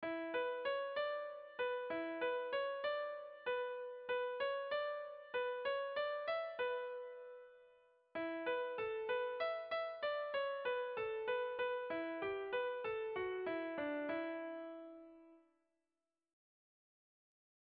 Erlijiozkoa
AB